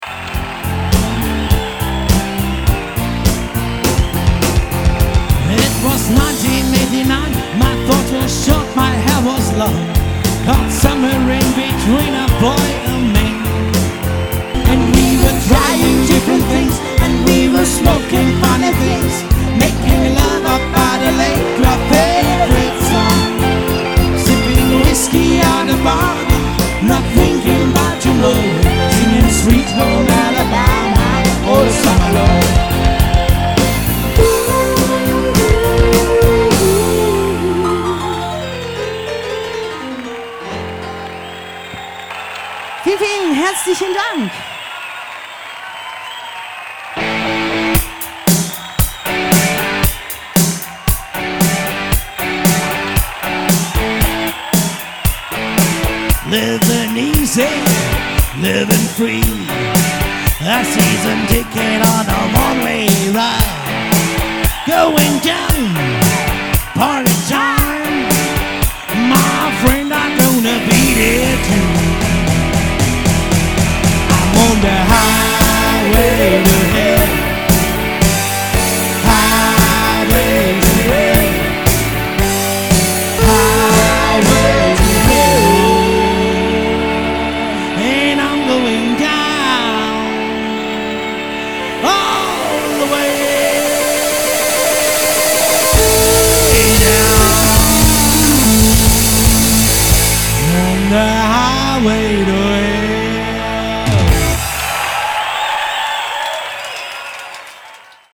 • Rock-Klassiker (diverse)